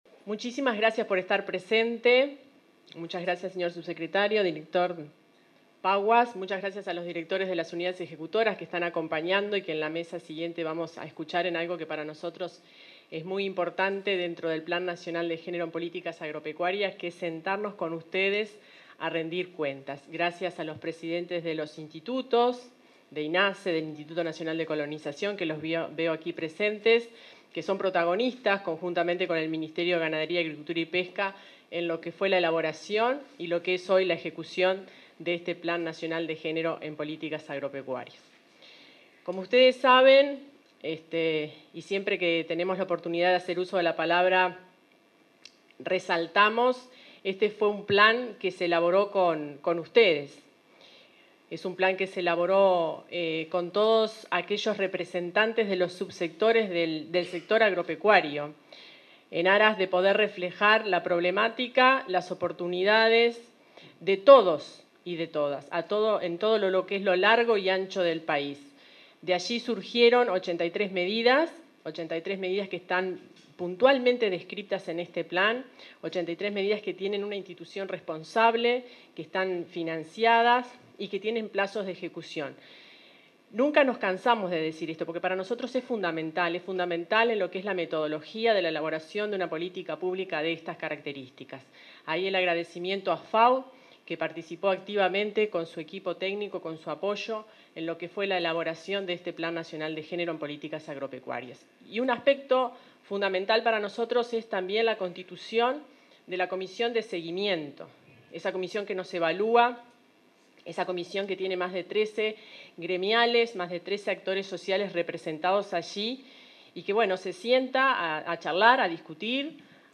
Palabras de autoridades en acto de Plan Nacional de Género en Políticas Agropecuarias
Palabras de autoridades en acto de Plan Nacional de Género en Políticas Agropecuarias 31/10/2022 Compartir Facebook X Copiar enlace WhatsApp LinkedIn En el marco del Plan Nacional de Género en las Políticas Agropecuarias, el Ministerio de Ganadería realizó el acto semestral de rendición de lo actuado y lanzó la plataforma de monitoreo y transparencia. Disertaron la directora general de la cartera, Fernanda Maldonado; el director de Agesic, Hebert Paguas; el subsecretario de Ganadería, Juan Ignacio Buffa, y la vicepresidenta de la República, Beatriz Argimón.